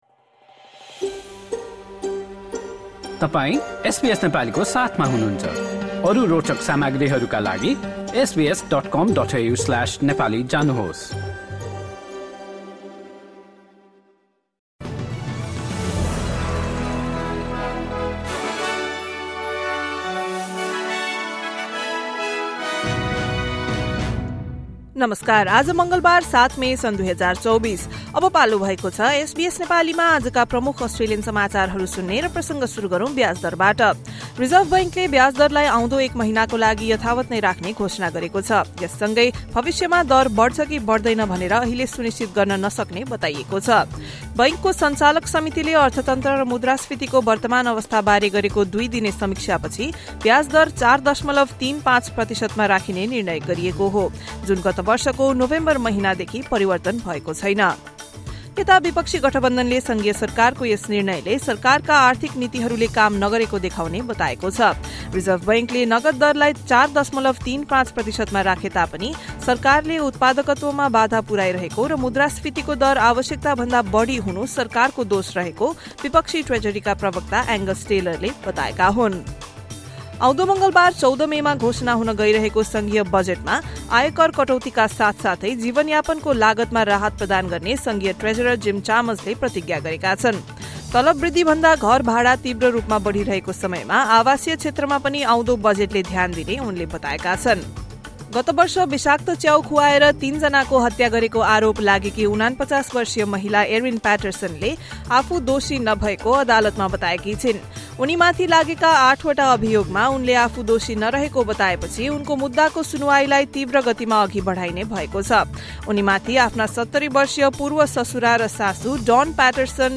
SBS Nepali Australian News Headlines: Tuesday, 7 May 2024